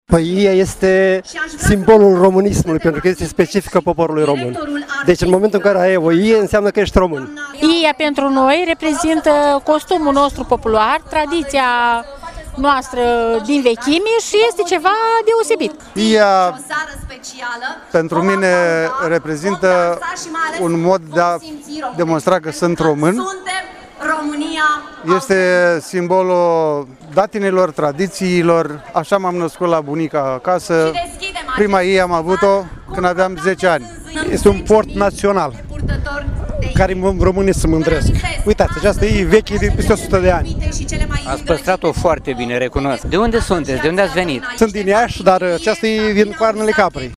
Piața Palatului și pietonalul Ștefan cel Mare din Iași găzduiesc, astăzi, Festivalul RomânIA autentică.
24-iunie-rdj-18-vox.mp3